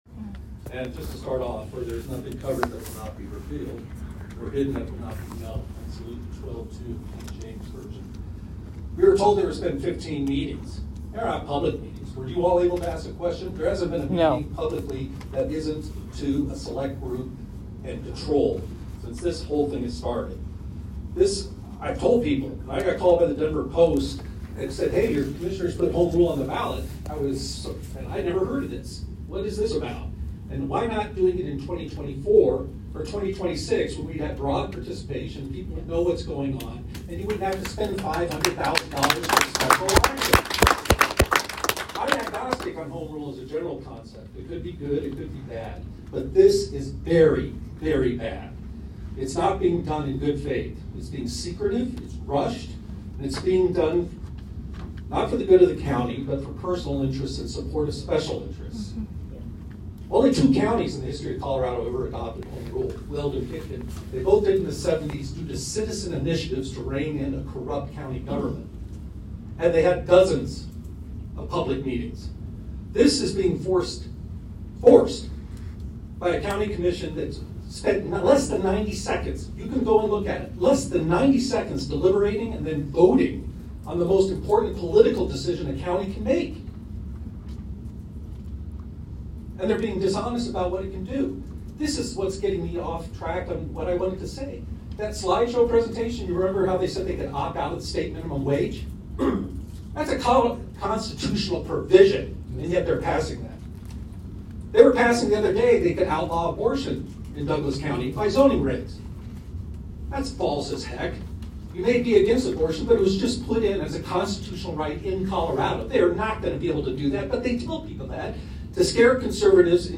But YOU can listen to the public comment Rep. Bob Marshall made at the HRMD meeting right here.